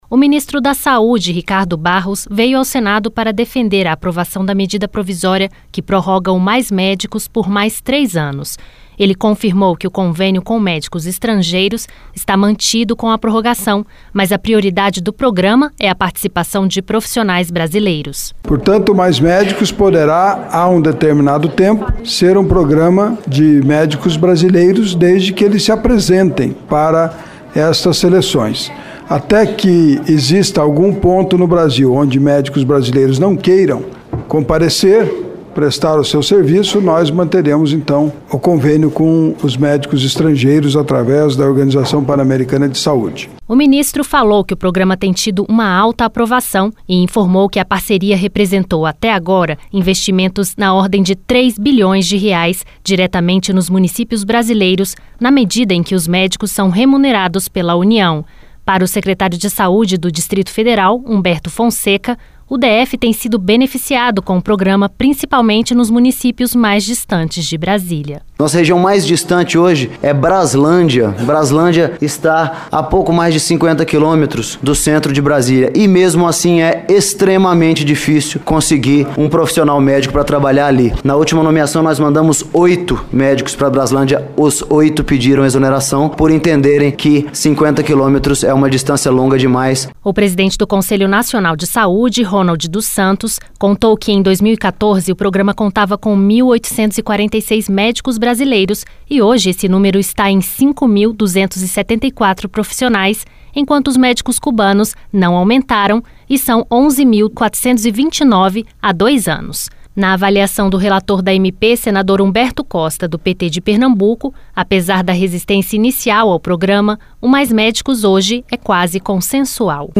A Comissão Mista que analisa a medida provisória que prorroga por mais três anos o Programa Mais Médicos ouviu nesta terça-feira o Ministro da Saúde, Ricardo Barros e representantes do Conselho Nacional de Saúde e dos prefeitos. Todos os convidados defenderam a prorrogação do programa e afirmaram que a prioridade do Mais Médicos é a adesão de profissionais brasileiros.